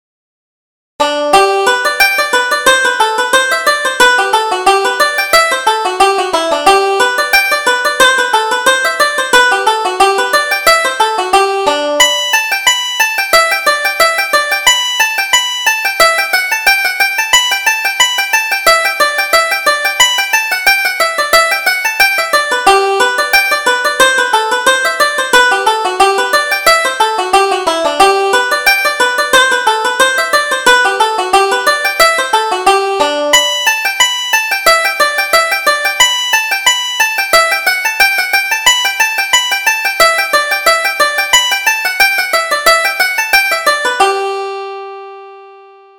Reel: Miss Thornton's Reel